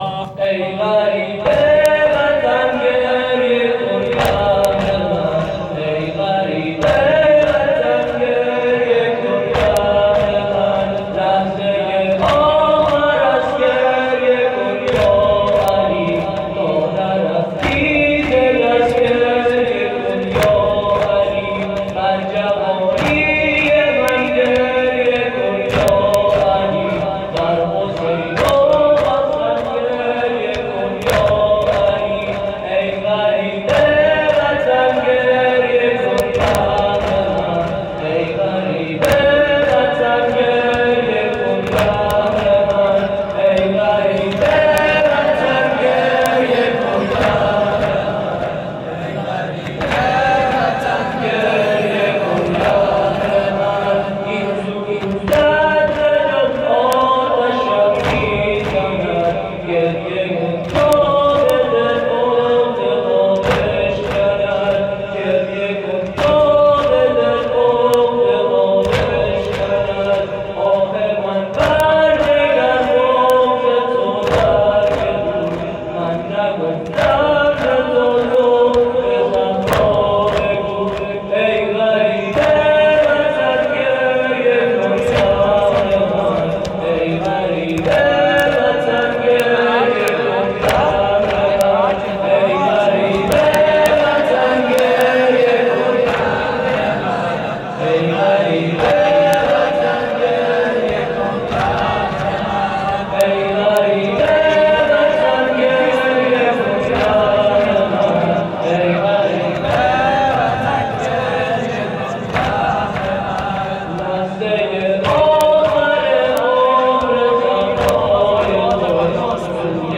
مداحی زابلی
5-واحدشلاقی-ای-غریب-وطن-گریه-کن-بهرمن.mp3